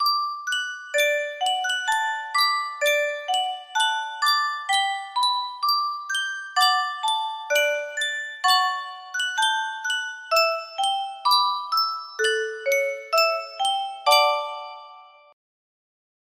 Sankyo Music Box - Michael Row the Boat Ashore GGE music box melody
Full range 60